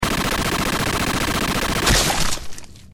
HasteJumpscare.MP3